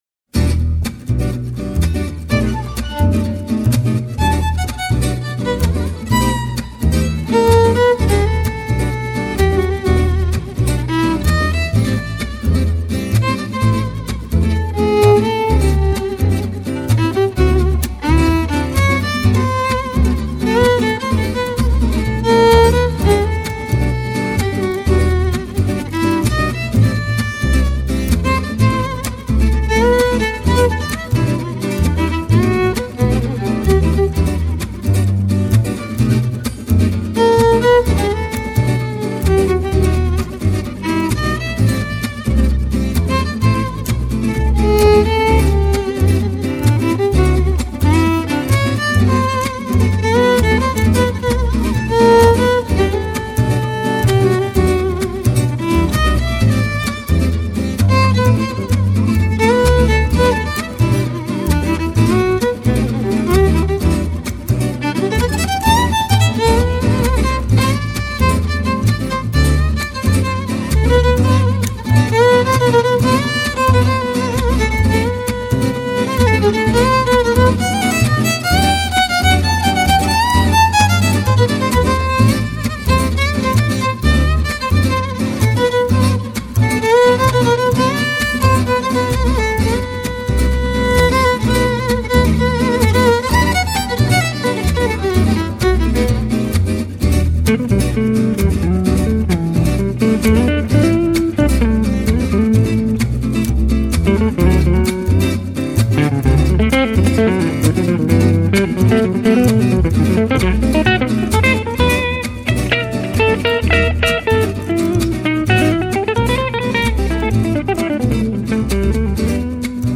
accordéon et accordina
guitares rythmiques
contrebasse